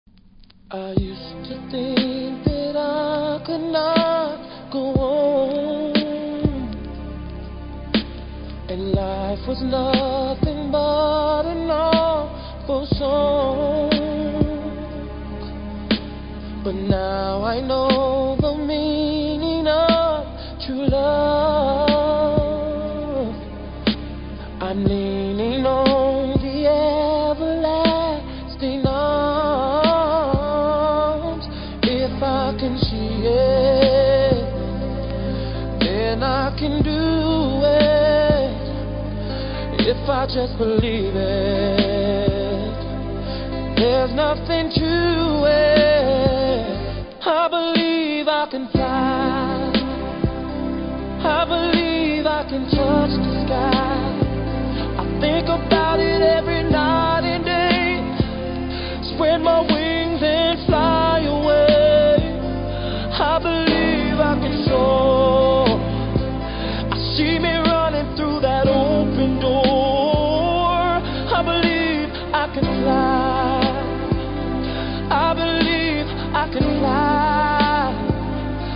盤に少し歪みありますがプレイは問題無いです。
ジュークボックス使用跡ありますが音には影響せず良好です。